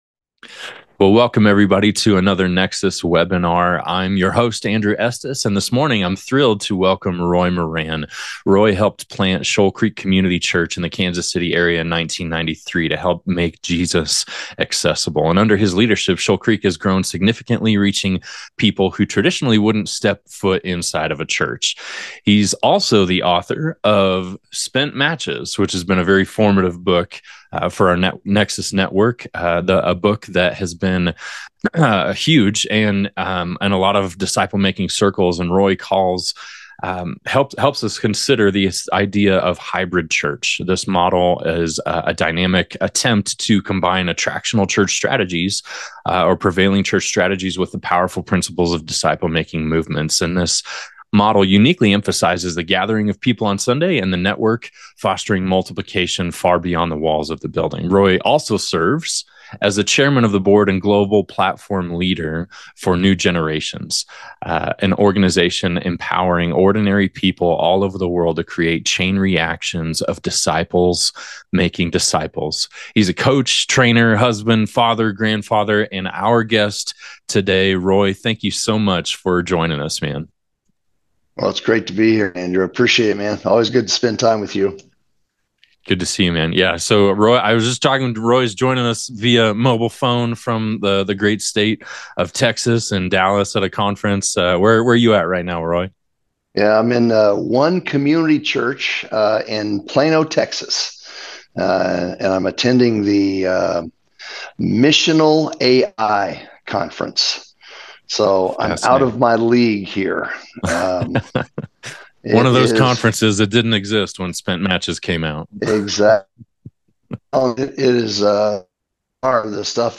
Join us for this webinar